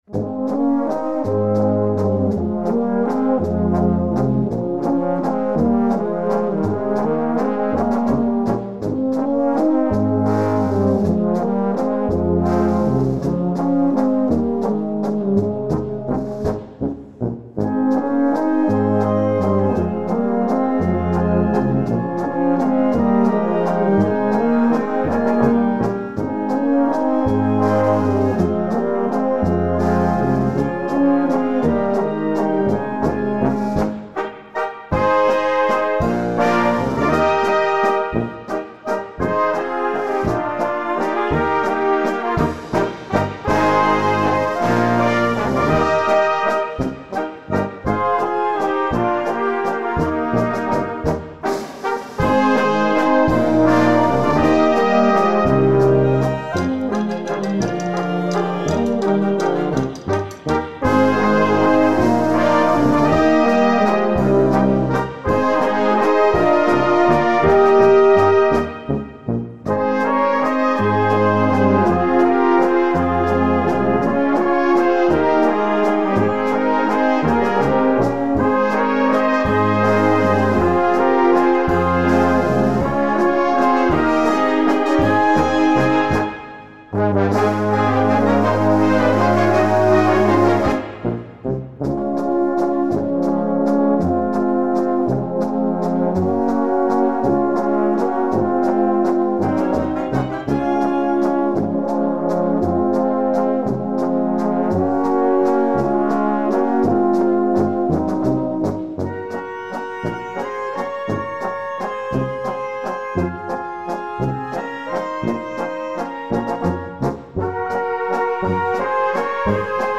Walzer
für Blasorchester